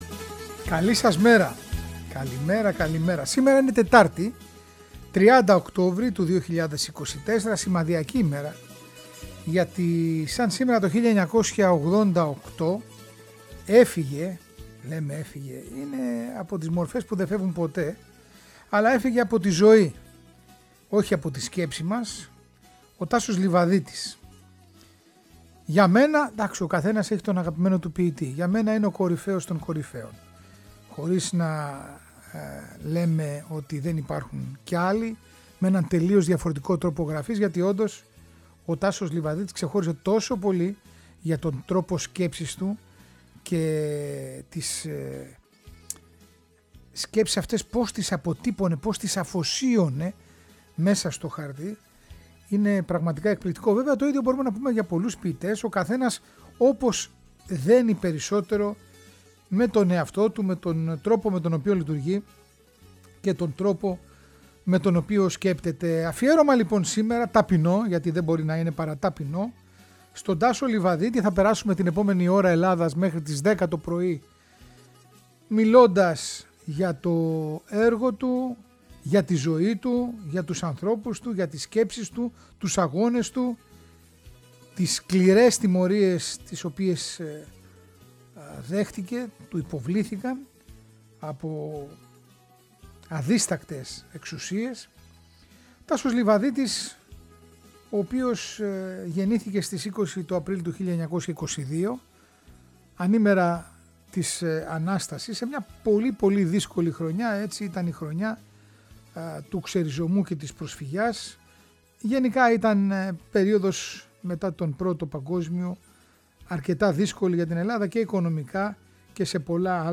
Αφηγήσεις από τον ίδιο… και τραγούδια του που μελοποιήθηκαν και άφησαν εποχή.